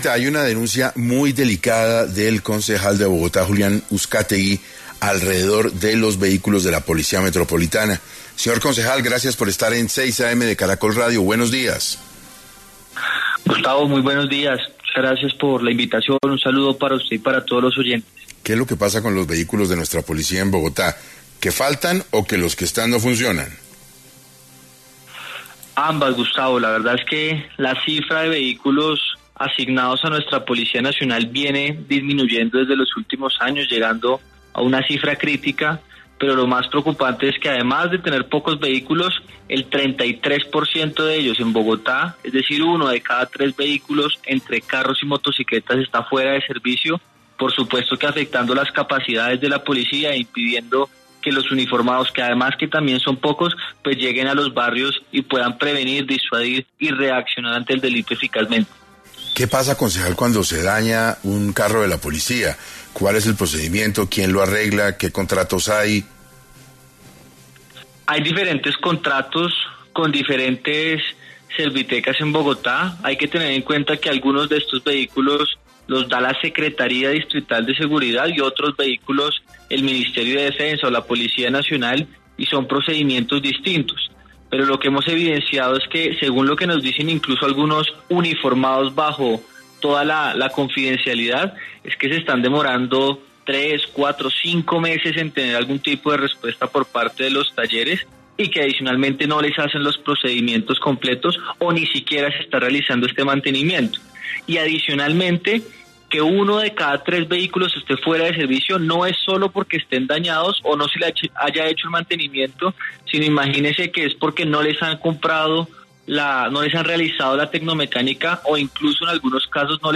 Julián Uscátegui, concejal, habló en 6AM sobre si hay “crisis operativa” en la Policía de Bogotá
Por los micrófonos de Caracol Radio, en su programa 6AM, pasó el concejal Julián Uscátegui, y habló sobre qué porcentaje de vehículos de la Policía en la capital del país están funcionando y como esto está afectando, no solo en Bogotá, sino en el demás territorio nacional.